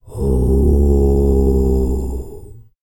TUVANGROAN02.wav